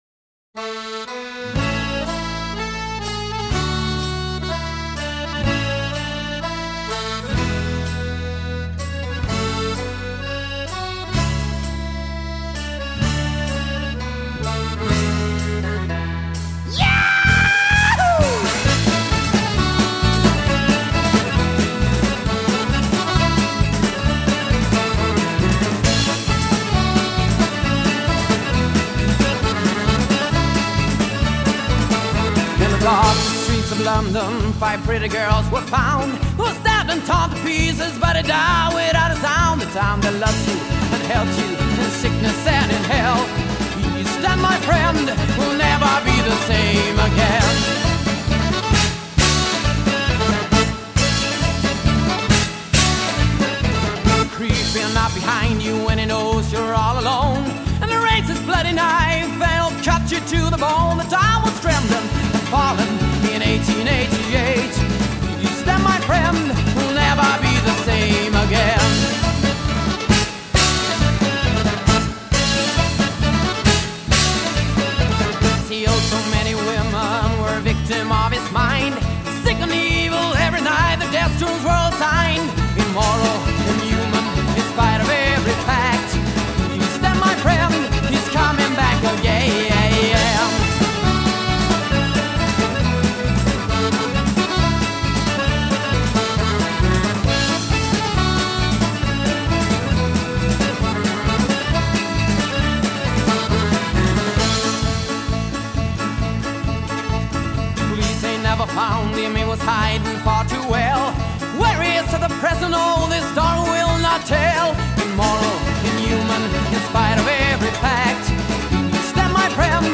Accordion
Bass
Mandolin
Drums